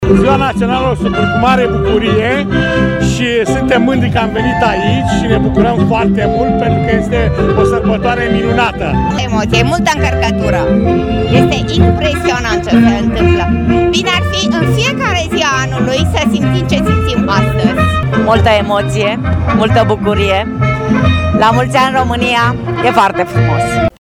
În Piața Sfatului din Brașov, grupurile de juni au încins cunoscuta horă a acestora, după care i-au invitat și pe cei prezenți să se prindă în marea Horă a Unirii, iar impresia participanților a fost una excelentă:
voxuri-hora-brasov.mp3